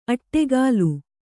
♪ aṭṭegālu